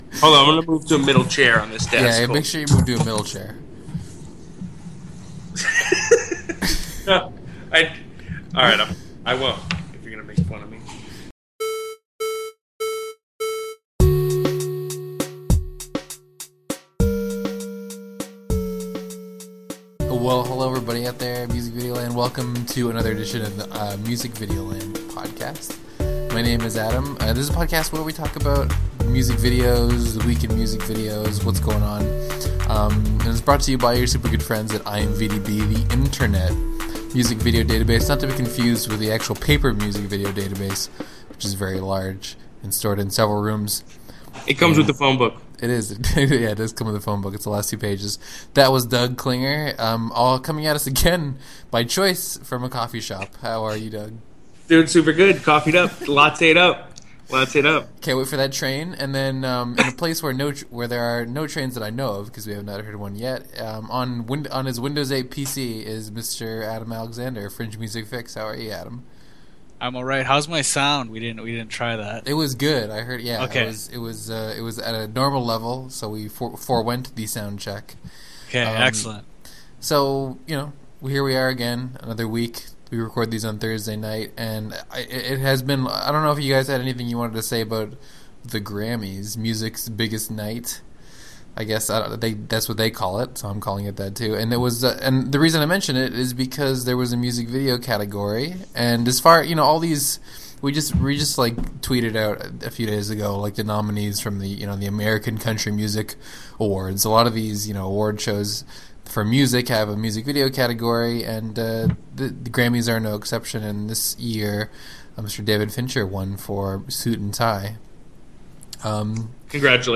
A weekly conversation about music video news and new releases.